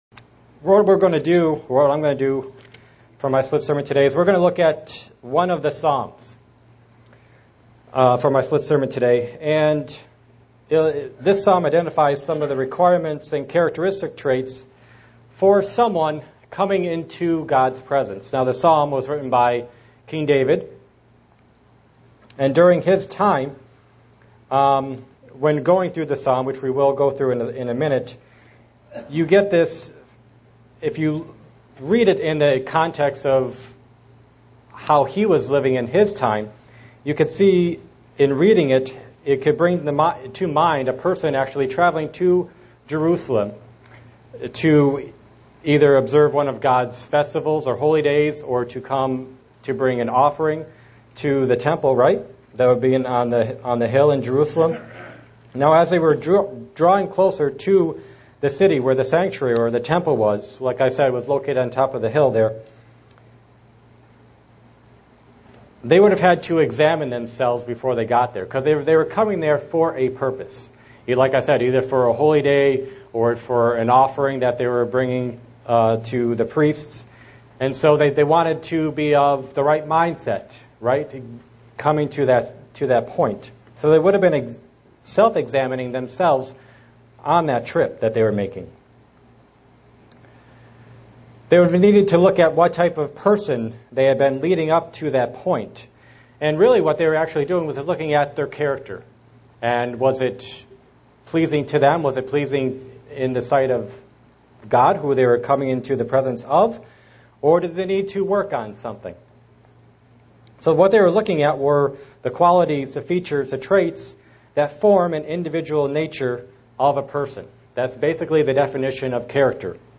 SEE VIDEO BELOW UCG Sermon Studying the bible?